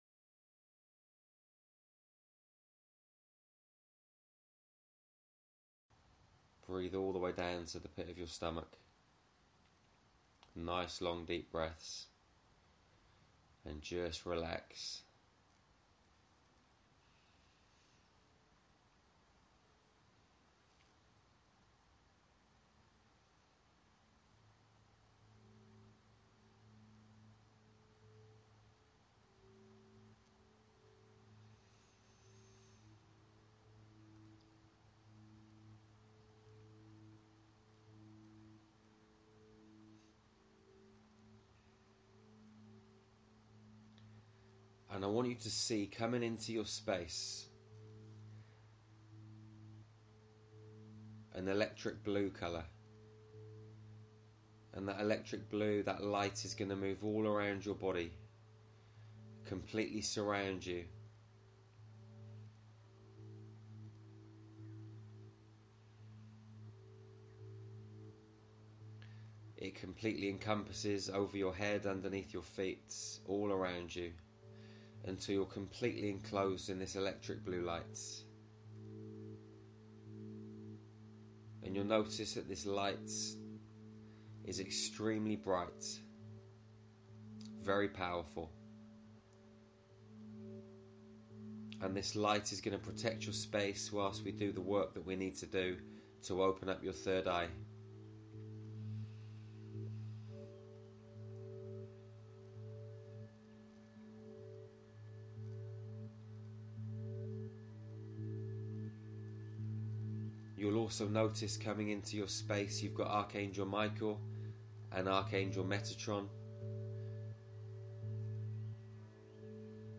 This meditation will take you into a space of peace and calmness. Any heaviness, worry, fear, will simply slide off from and out from your body, it will disappear from your consciousness, as it transmutes into positive energy and pure white light.